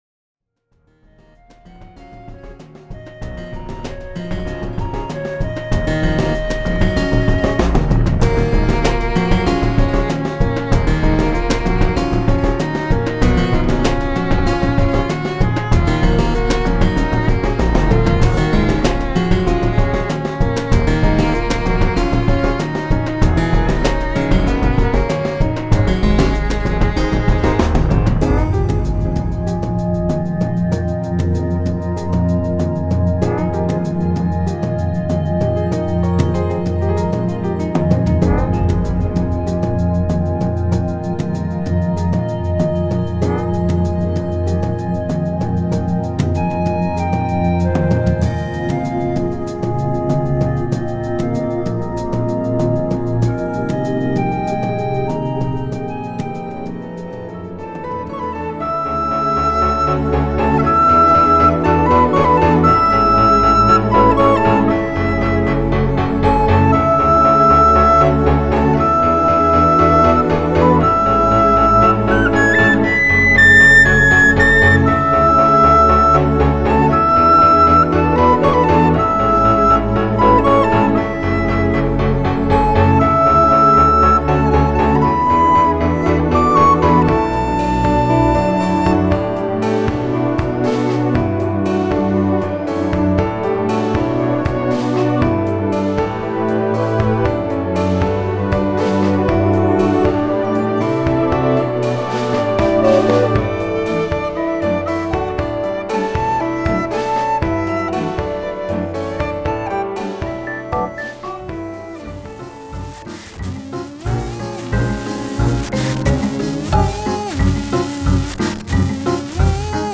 全曲クロスフェード